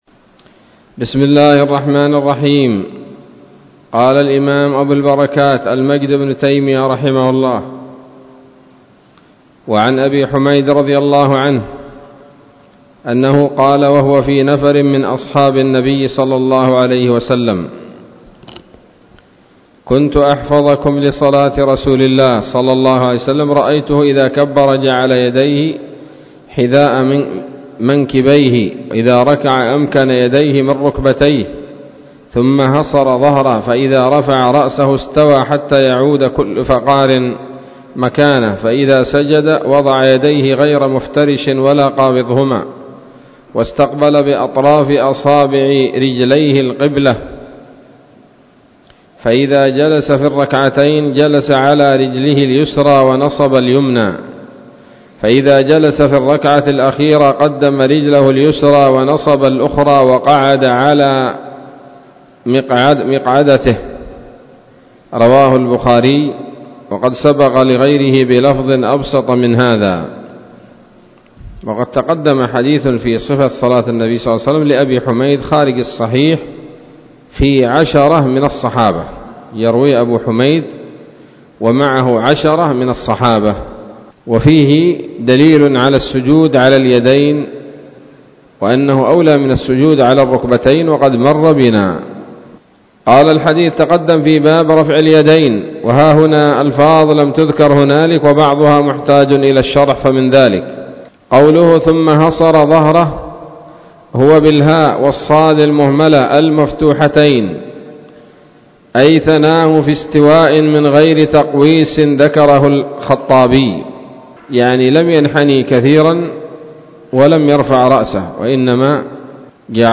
الدرس الثالث والسبعون من أبواب صفة الصلاة من نيل الأوطار